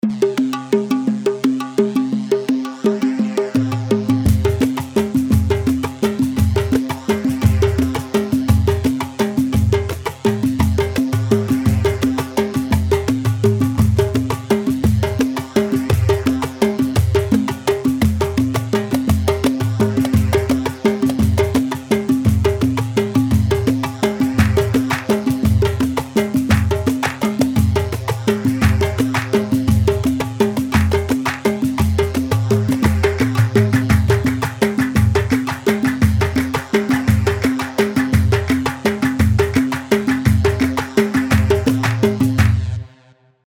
Yemeni
Sharh Yemeni 3/4 170 شرح يمني